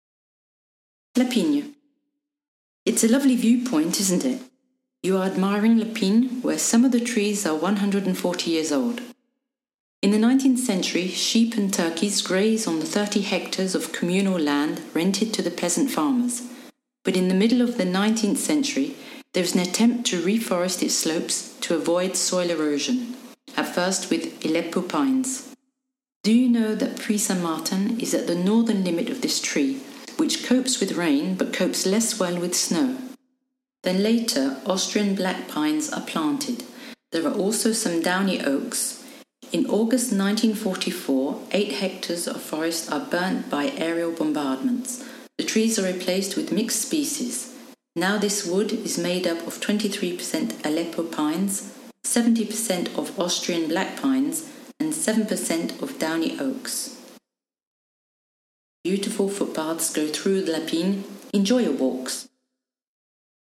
She will be your guide, and will lead you on to the discovery of this village which she loved so much. By scanning with a “smartphone” on the QR code shown below and on each panel, the visitor will have access to an audio guide in English, which will enrich their experience.